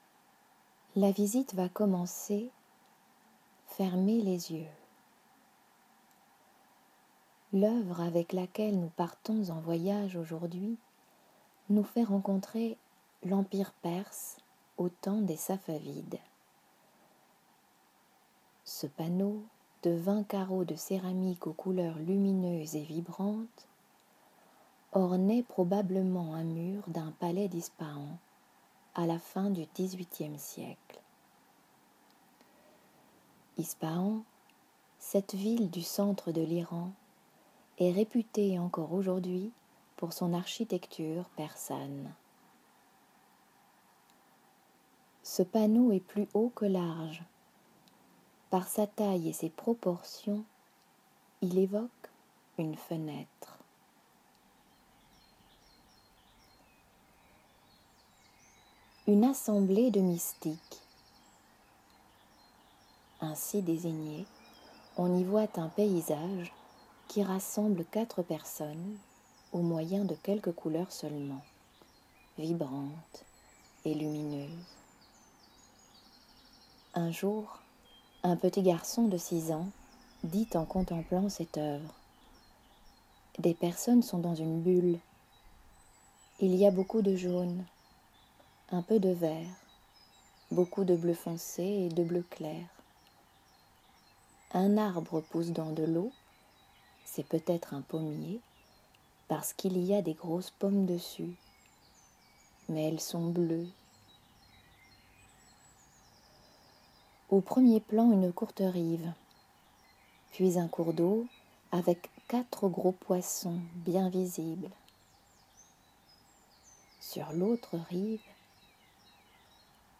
Louvre-Lens > Mon Louvre-Lens > Lectures audiodescriptives > LE PANNEAU DES MYSTIQUES